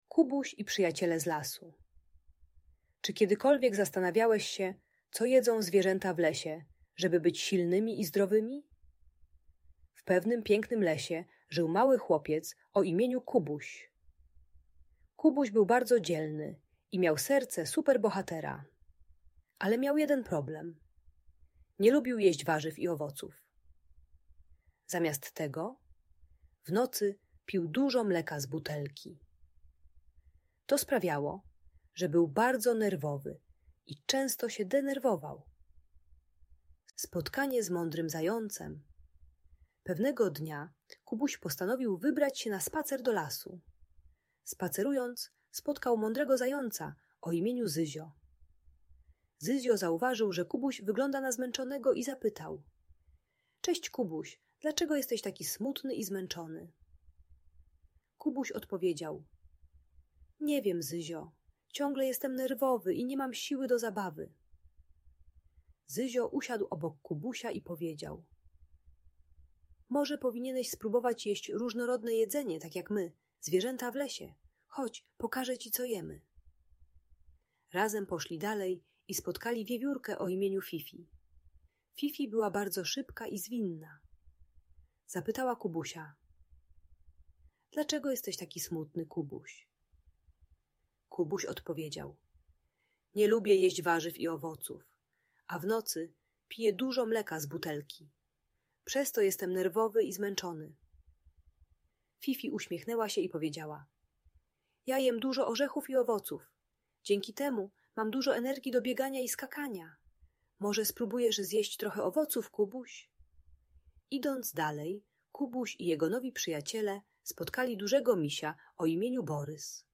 Kubuś i Przyjaciele - Problemy z jedzeniem | Audiobajka